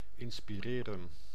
Ääntäminen
IPA: [dik.te]